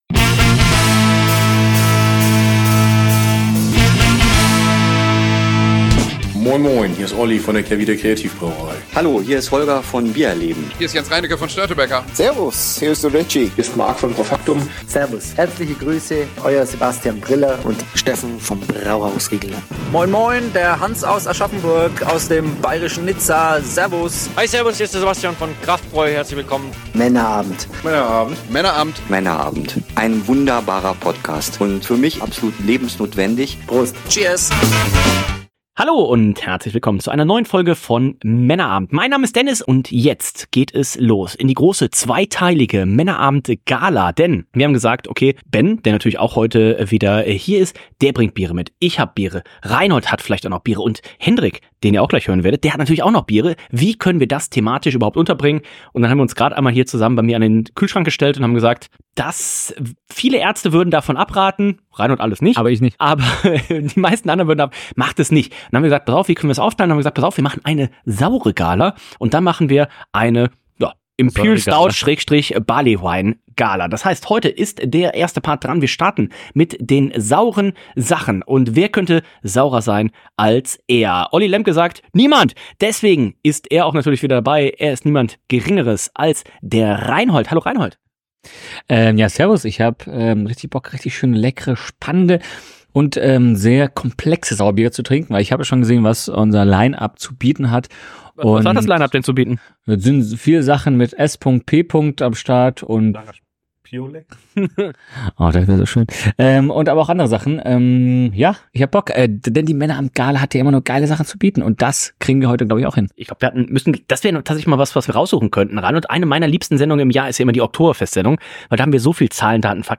In lauschiger Runde diskutieren wir bei der einen und anderen Flasche Bier, Wein oder Genever über das aktuelle Weltgeschehen